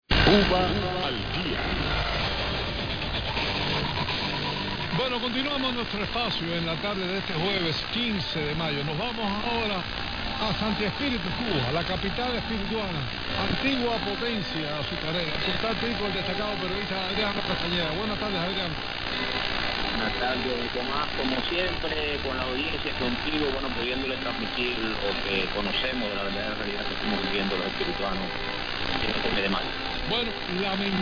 Attached is a recording of right now, local Radio Rebelde Transmitter off and Radio Martí is coming strong, and here you have a picture of the SDR waterfall.